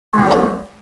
Heroes3_-_Pit_Fiend_-_DefendSound.ogg